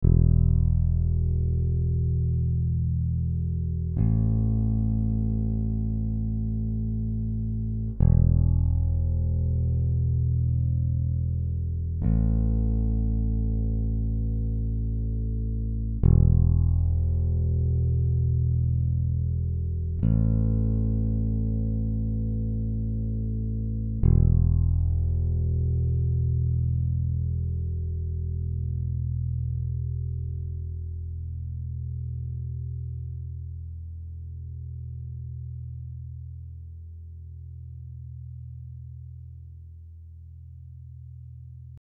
Pro porovnání je to brnkání prázdné E a A struny (jde to E, A, E, A, E, A, E a dozvuk). Obojí samozřejmě čistý signál z basy do zvukovky, bez úprav.
E struna - hra prsty